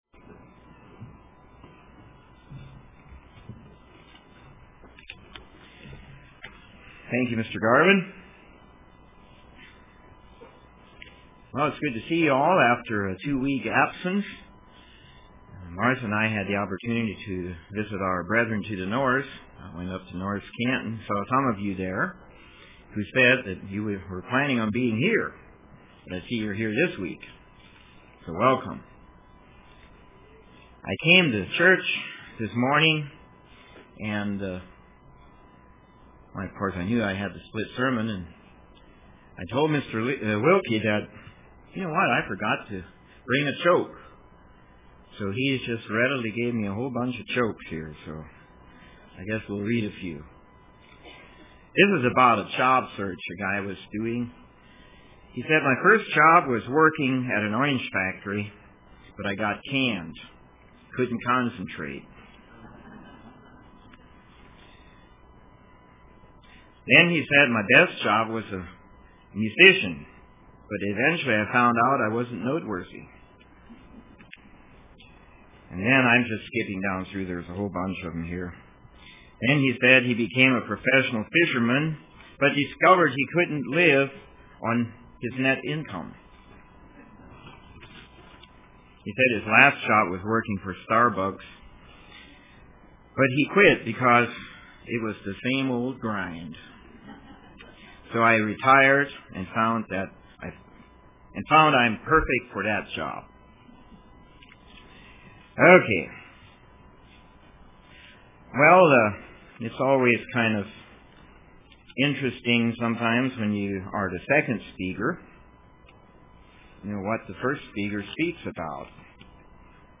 Print The Kingdom of God UCG Sermon